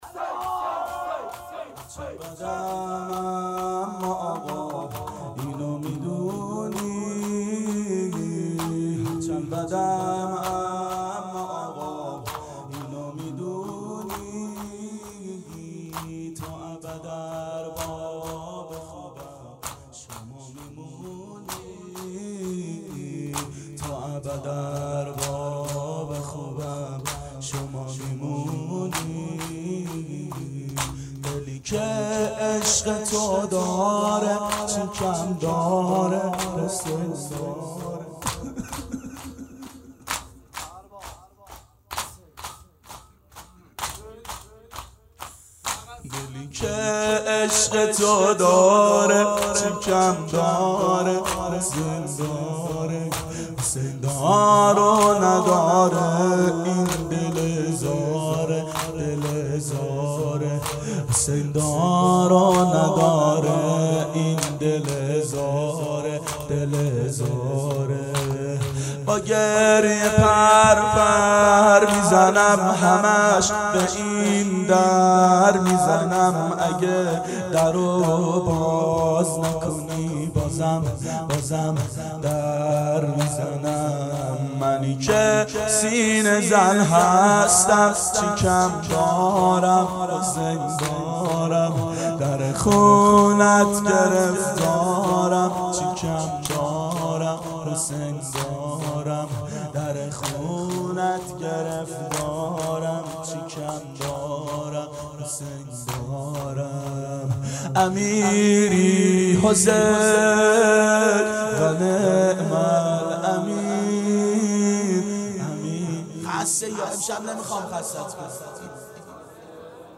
دهه اول صفر سال 1390 هیئت شیفتگان حضرت رقیه س شب اول